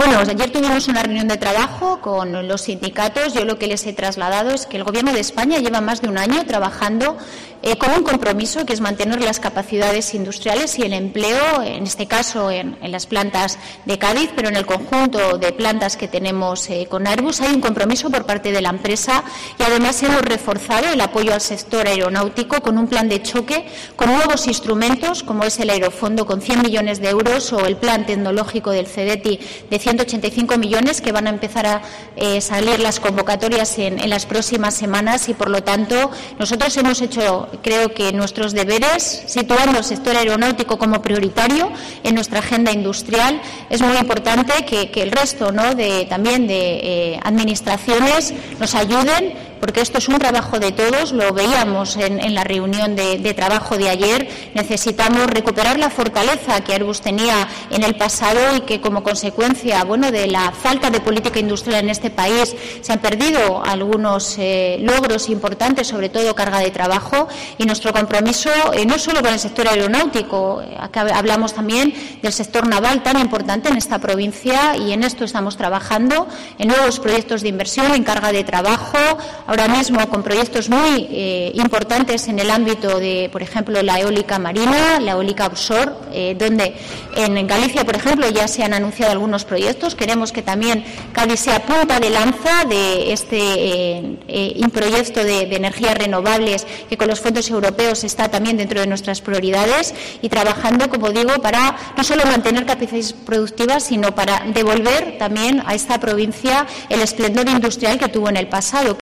AUDIO: Reyes Maroto, ministra de Industria, en su visita a Cádiz